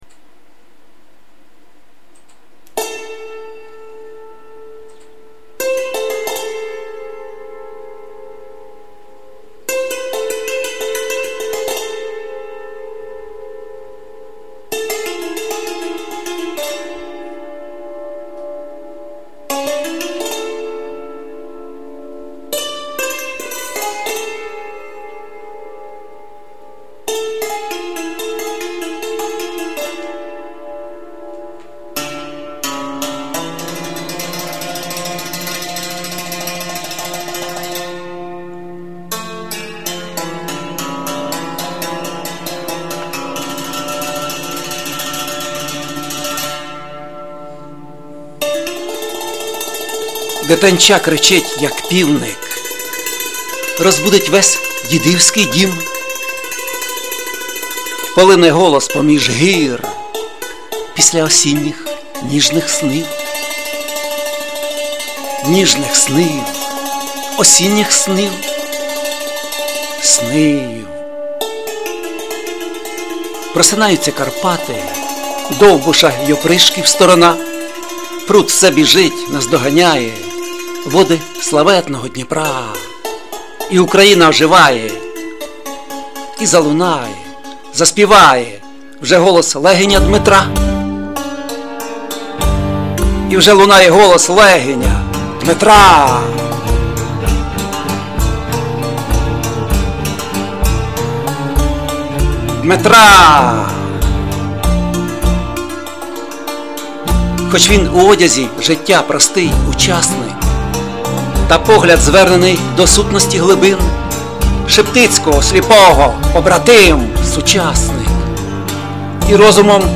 щиро дякуємо за мелодію і поетичне прочитання поезій "Голос"